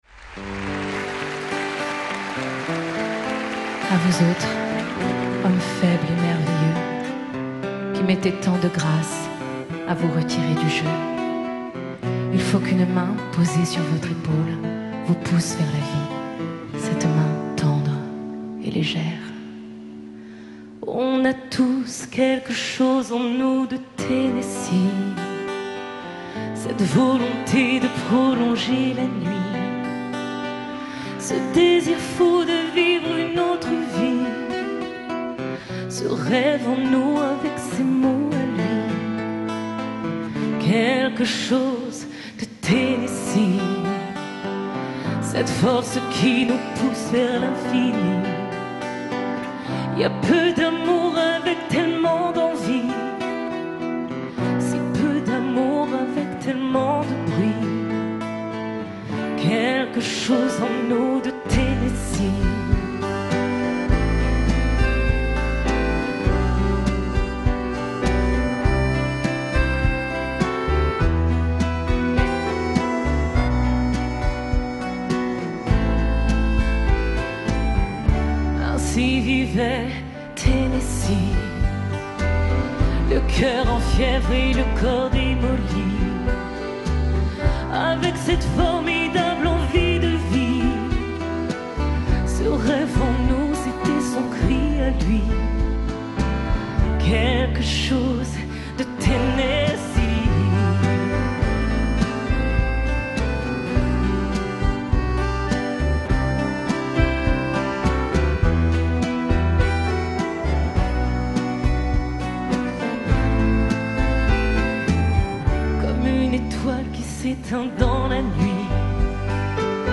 Sur la scène de la Seine musicale (à Boulogne-Billancourt)